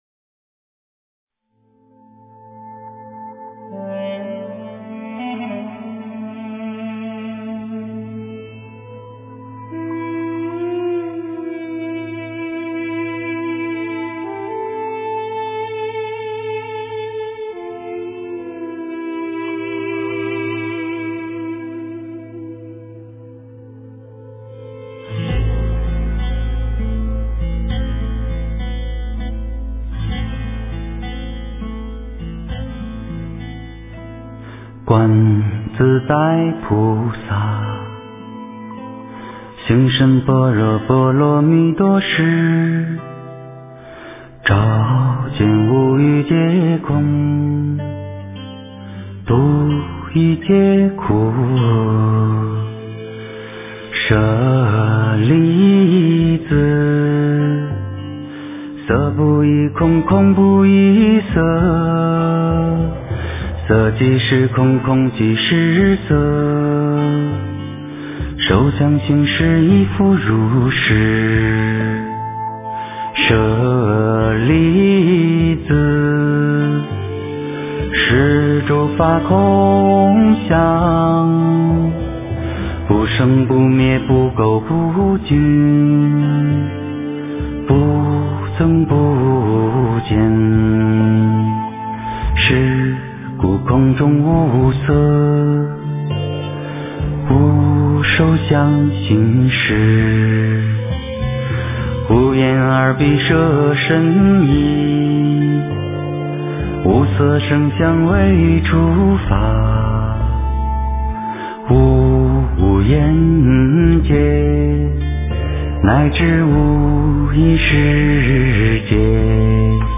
诵经
佛音 诵经 佛教音乐 返回列表 上一篇： 消灾吉祥神咒 下一篇： 阿妈佛心上的一朵莲 相关文章 大法音 大法音--佛教音乐...